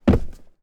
player_jump_land.wav